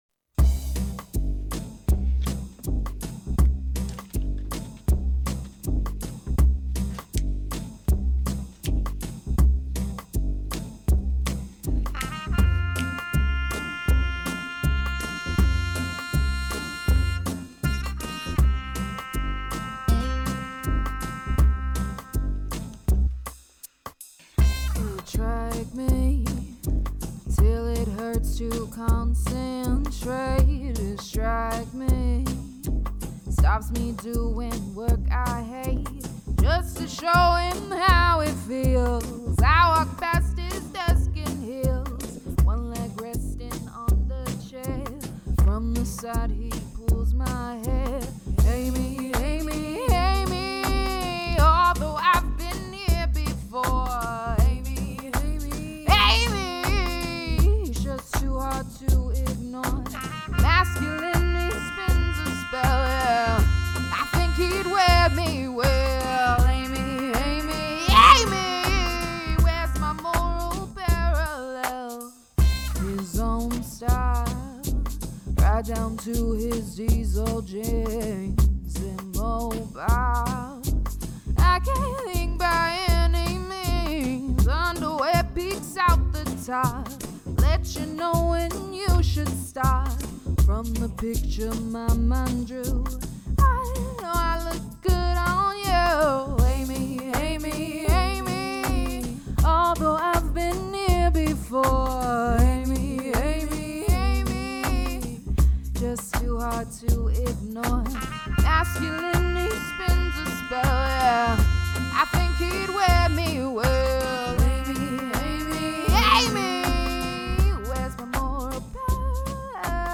amy-amy-amy-vocals.finalcut.mp3